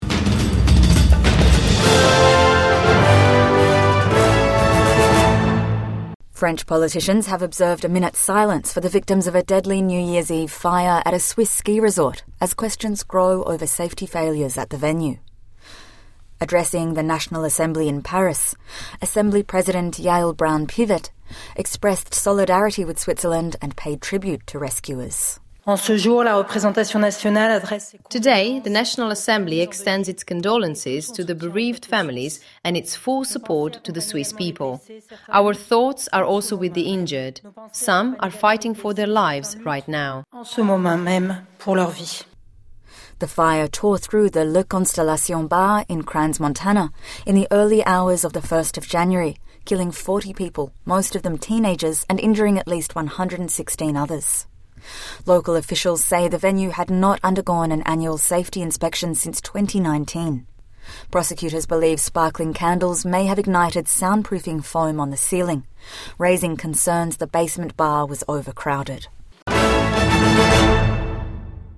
French politicians observe a minute’s silence for victims of deadly fire at Swiss ski resort